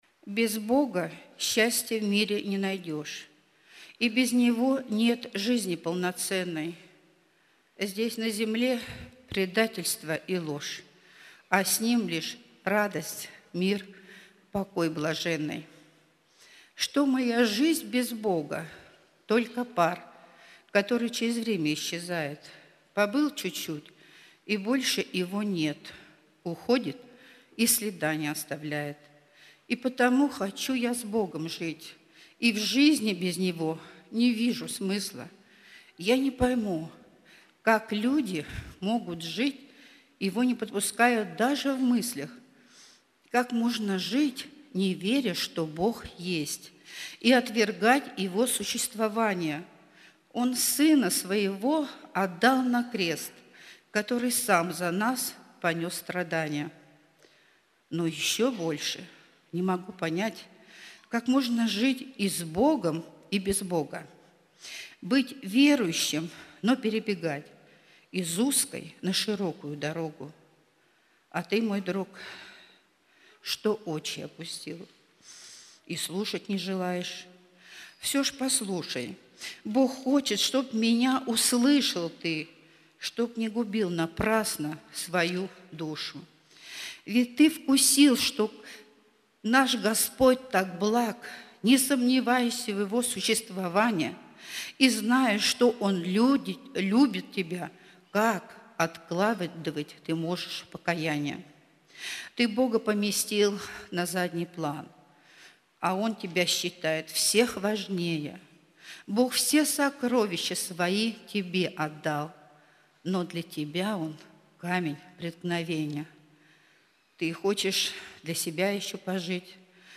Богослужение 29.01.2023
(Стихотворение)